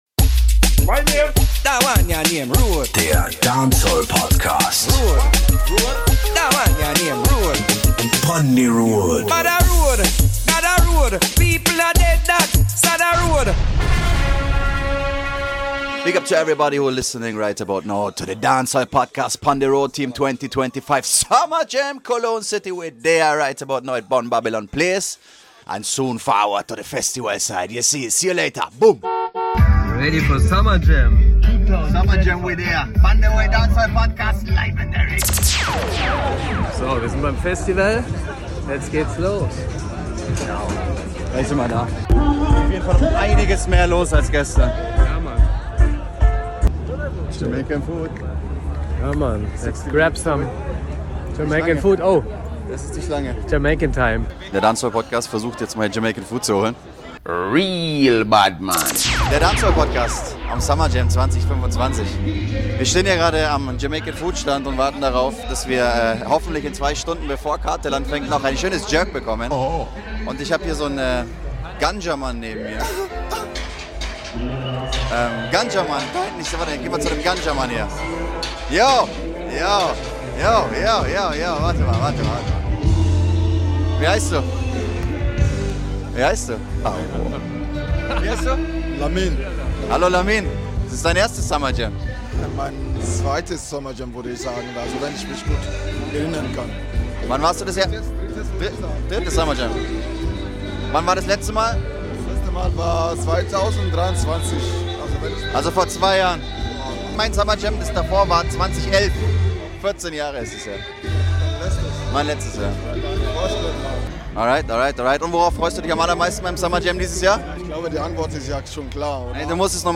Auch nach dem Festival haben noch die JUGGLERZ, WARRIOR SOUND, SUNGUN SOUND und weitere Menschen Reviews & Meinungen auf unseren DANCEHALLPODCAST ANRUFBEANTWORTER gesprochen.